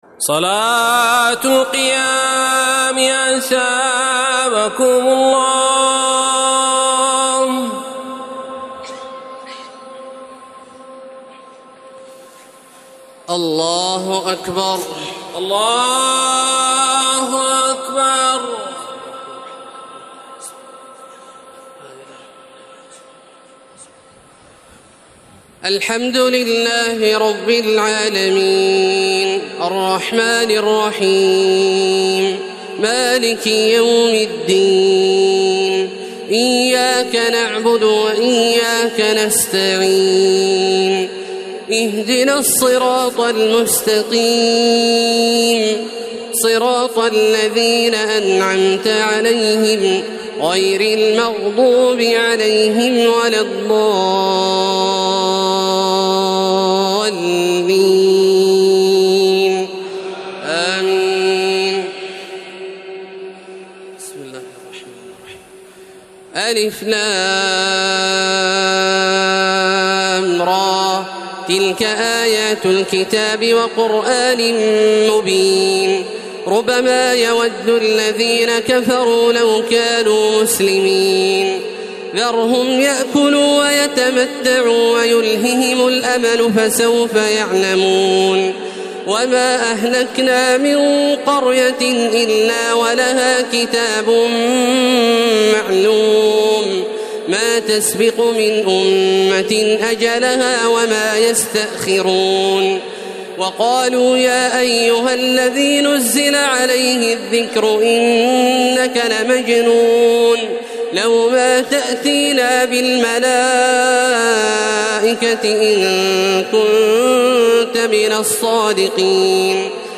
تراويح الليلة الثالثة عشر رمضان 1432هـ من سورتي الحجر كاملة و النحل (1-52) Taraweeh 13 st night Ramadan 1432H from Surah Al-Hijr and An-Nahl > تراويح الحرم المكي عام 1432 🕋 > التراويح - تلاوات الحرمين